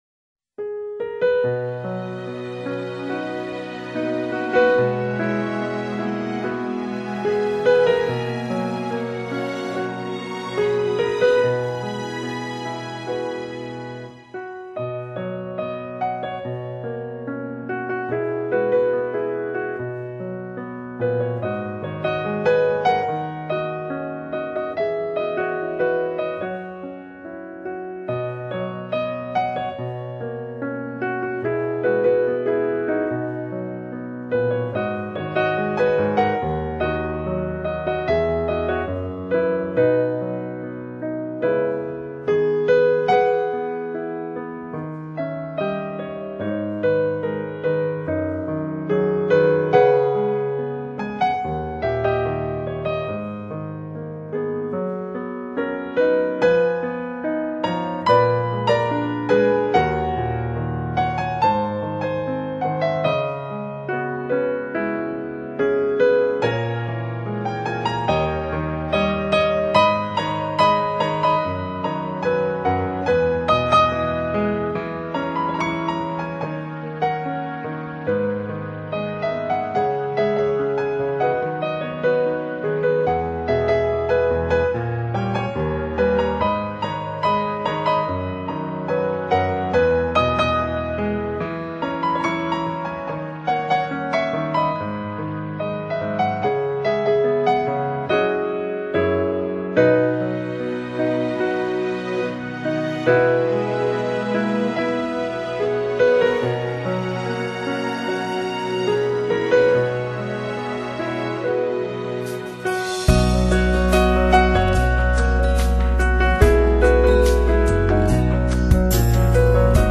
婚礼婚庆
该BGM音质清晰、流畅，源文件无声音水印干扰，